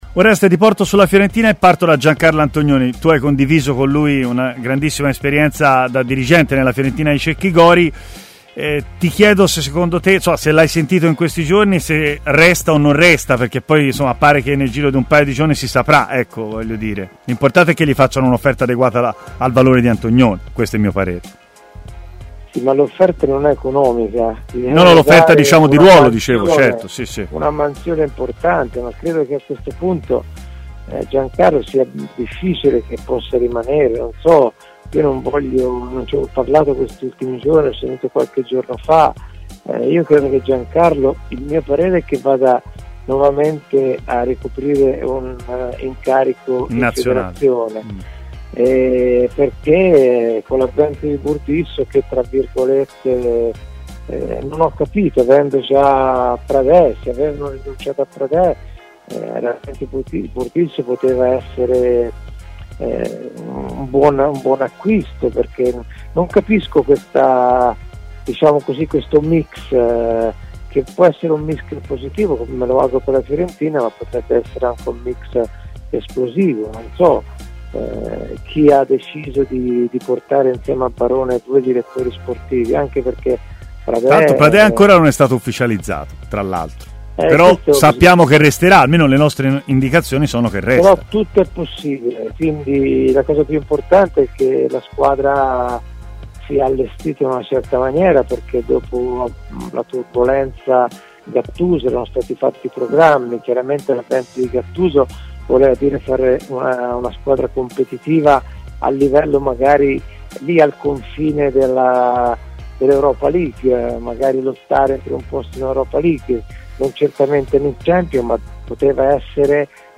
in diretta a Stadio Aperto, trasmissione di TMW Radio